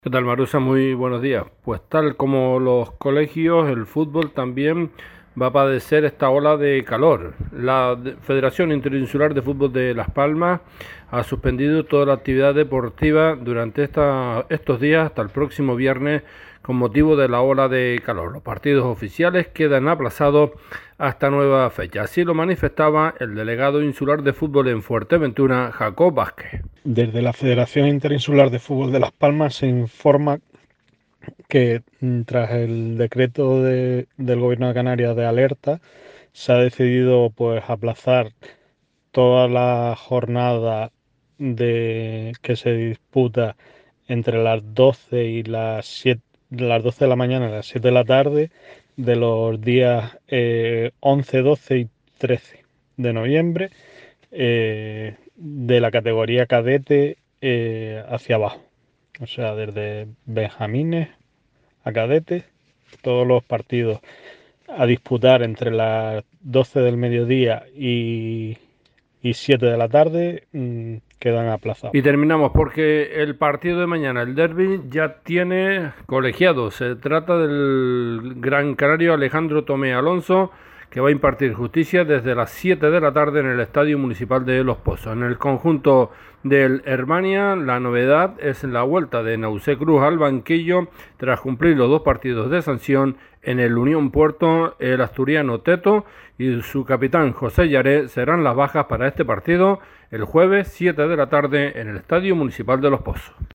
A Primera Hora, crónica deportiva